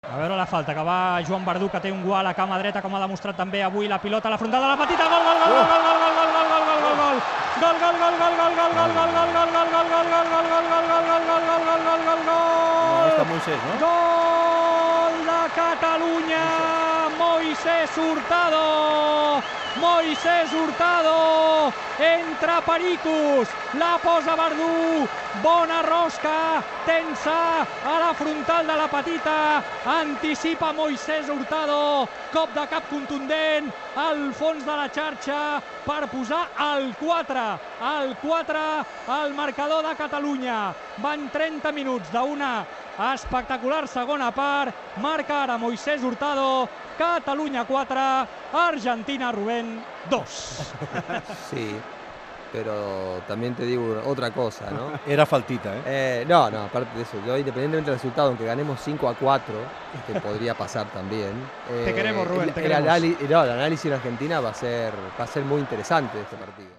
Narració dels gols del partit entre les seleccions de Catalunya i Argentina
Esportiu